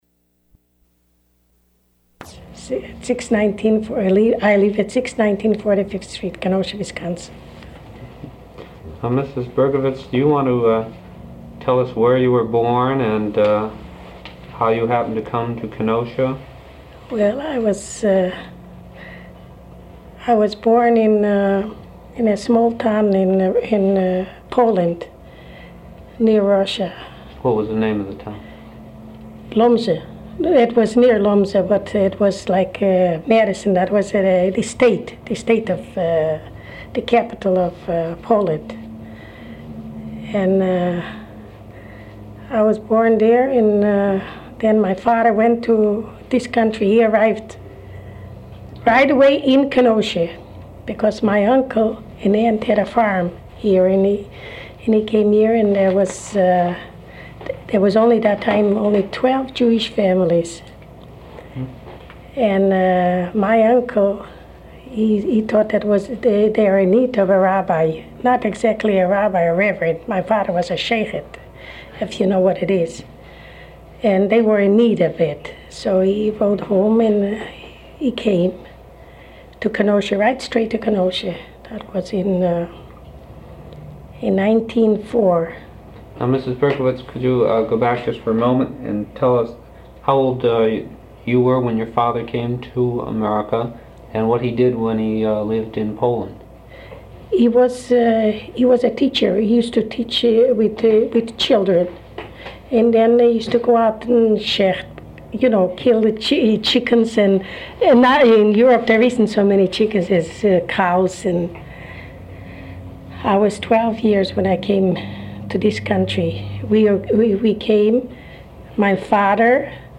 Interview
Wisconsin Historical Society Oral History Collections